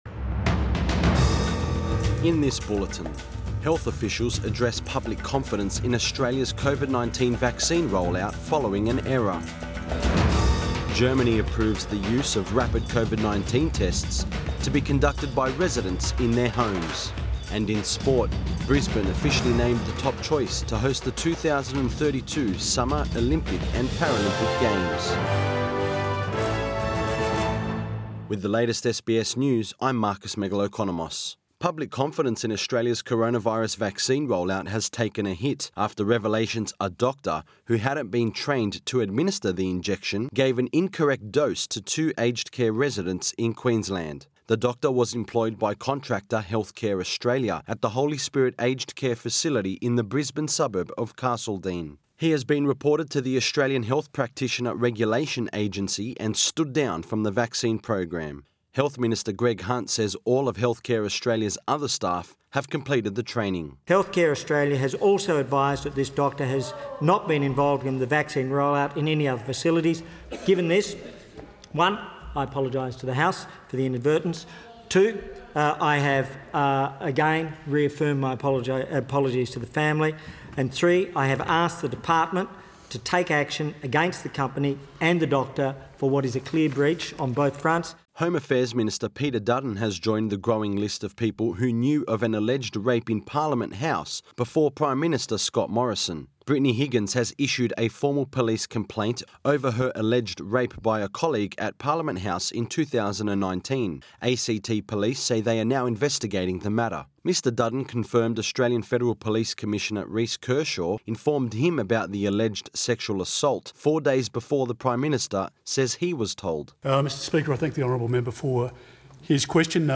AM bulletin 25 Feb 2021